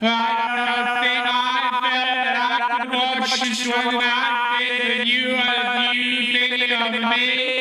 7 Harsh Realm Vox Repeat Long.wav